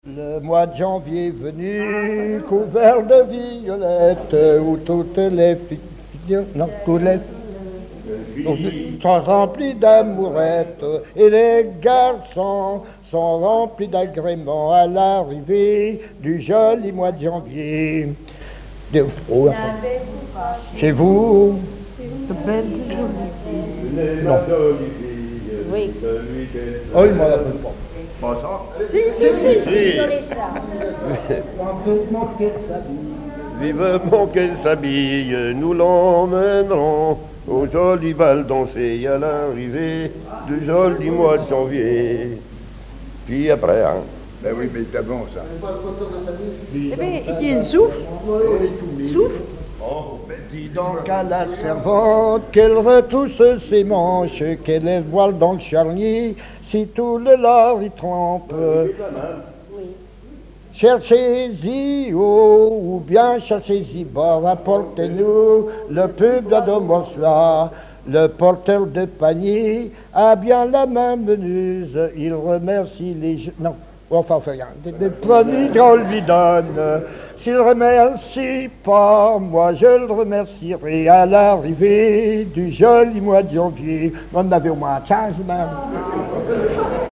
Genre strophique
Regroupement de témoins ; chanteuses, chanteurs, musiciens
Pièce musicale inédite